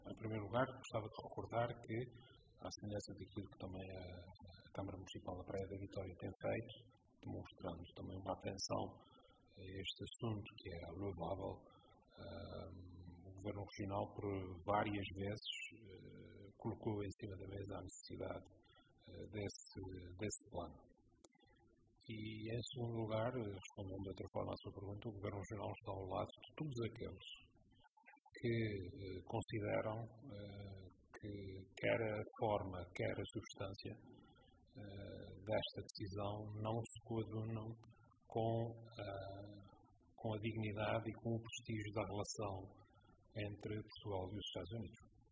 Em declarações aos jornalistas, Vasco Cordeiro adiantou que, no âmbito deste processo, já esteve em contacto com o Ministro dos Negócios Estrangeiros e que já foram solicitadas audiências, com caráter de urgência, ao Presidente da República e ao Primeiro-Ministro, com quem pretende fazer uma “abordagem detalhada” deste assunto.